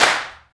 35 CLAP   -L.wav